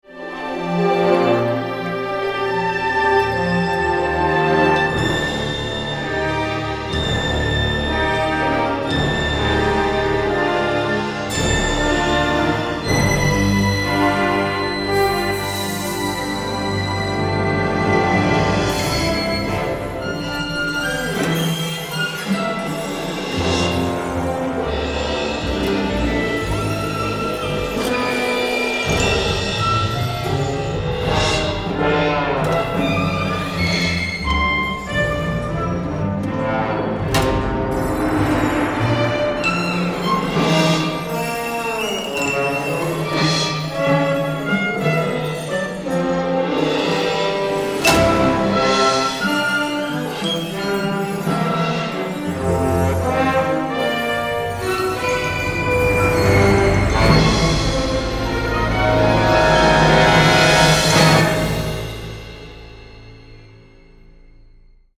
for orchestra and live electronics